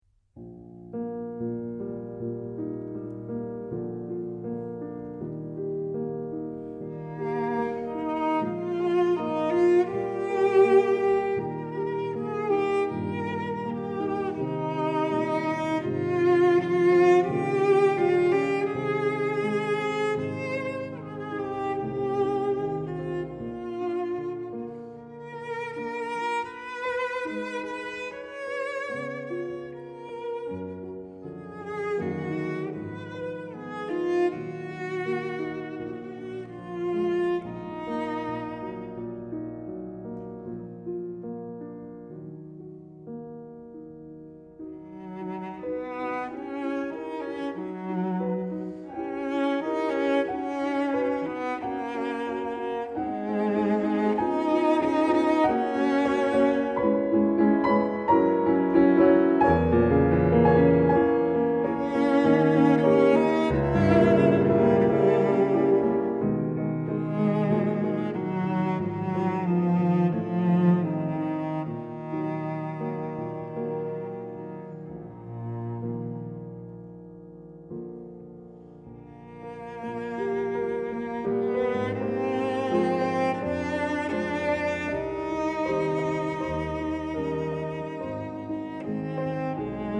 Cello
Piano)audio_joy.JPG